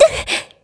Ripine-Vox_Damage_kr_01.wav